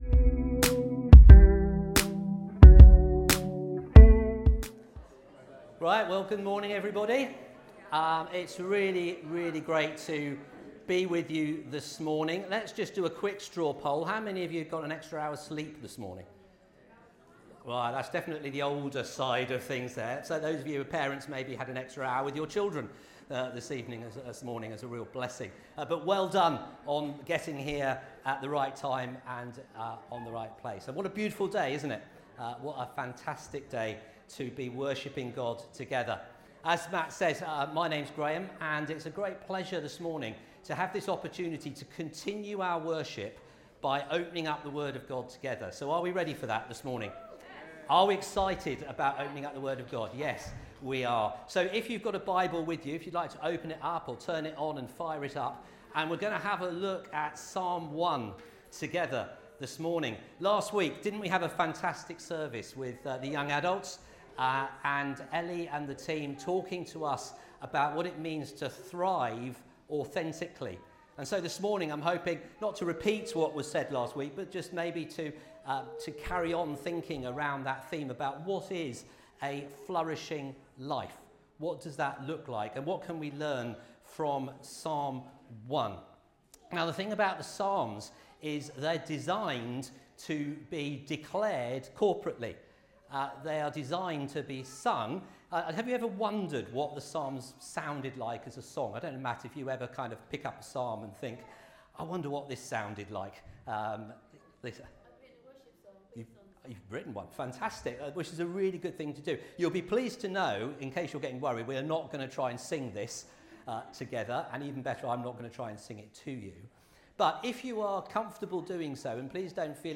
Sunday Messages